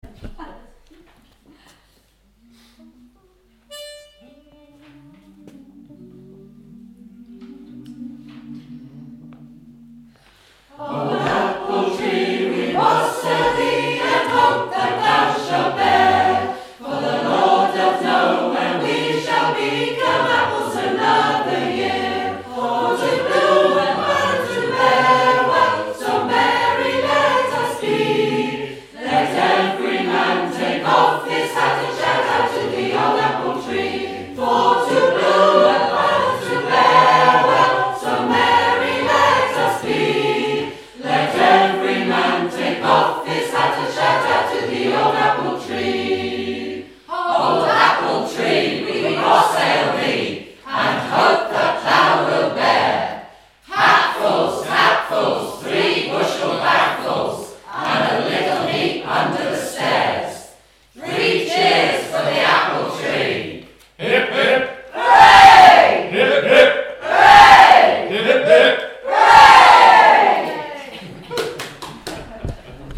All Parts Soprano Alto Tenor Bass Score and Parts Words The parts on this recording are slightly different to the parts above; the tune is the same.